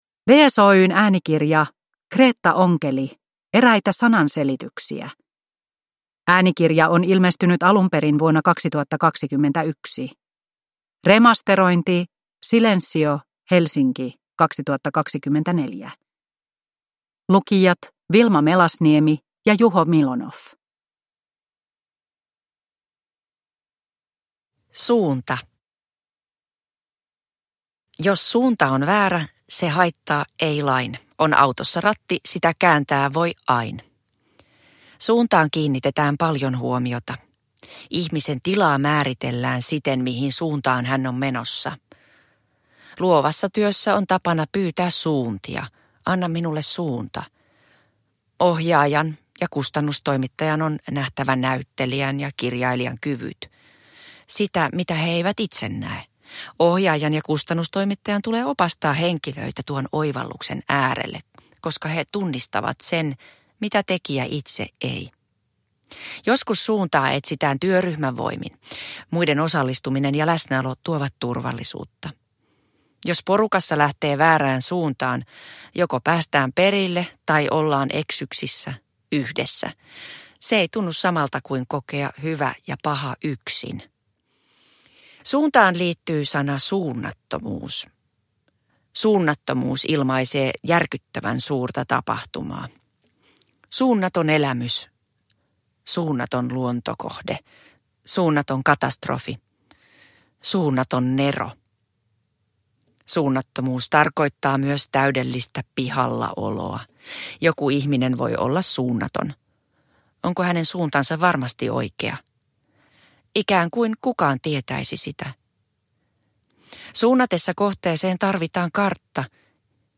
Eräitä sananselityksiä – Ljudbok